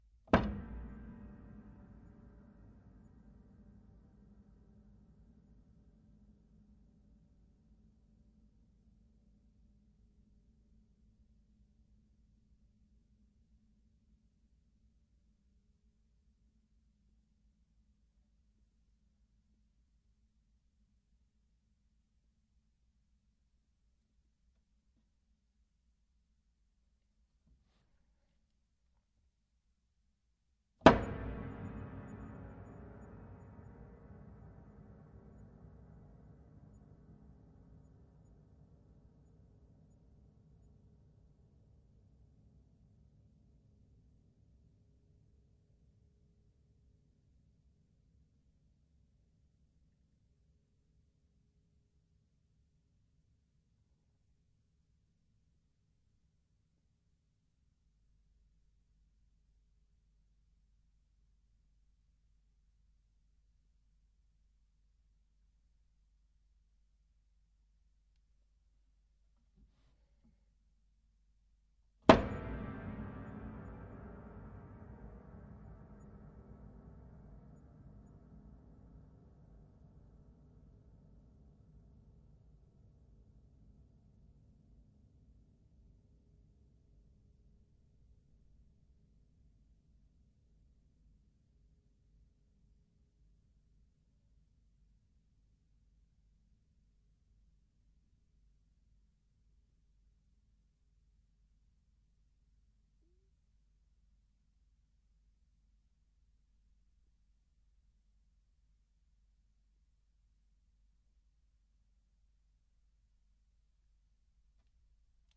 描述：立体声，24bit / 48kHz，Lewitt Authentica LCT 640
Tag: 维持 钢琴 踏板 内饰